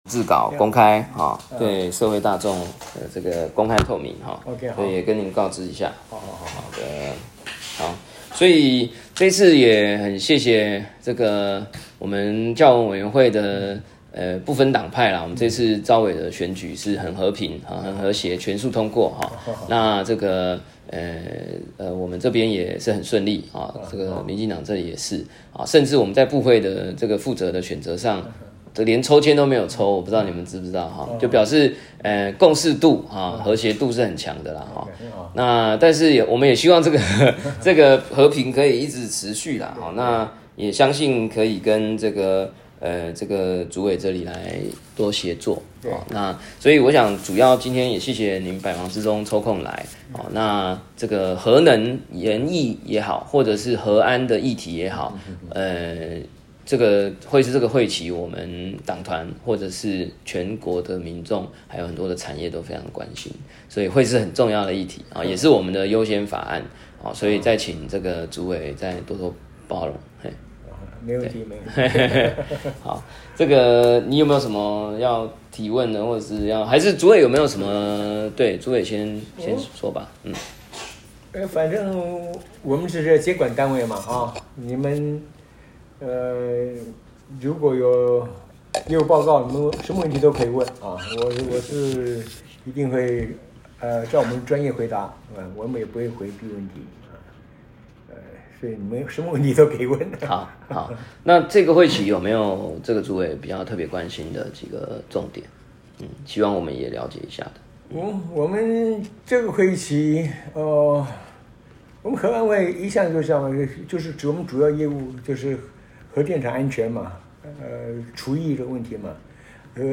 時間：2025-03-07 與會人士：葛如鈞委員、核安會 - 1、葛如鈞委員辦公室